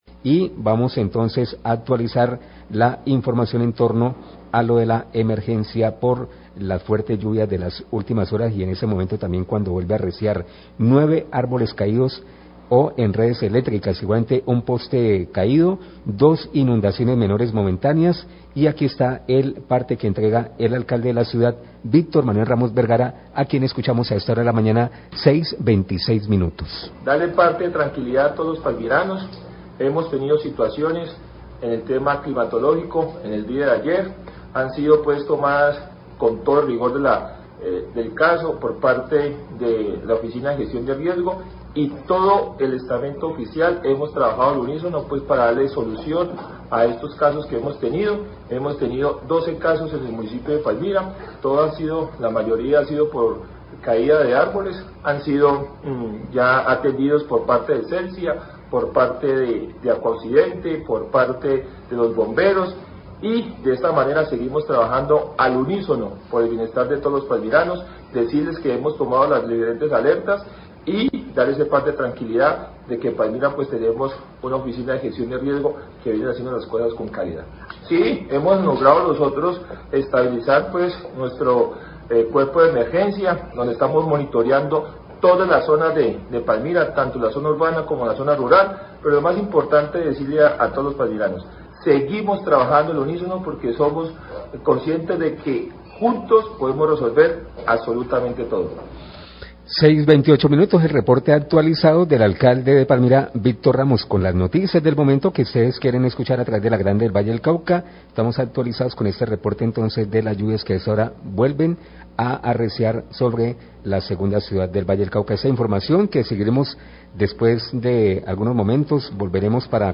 Alcalde Palmira da reporte sobre emergencias por fuertes lluvias como caída de árboles
Radio